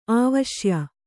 ♪ āvaśya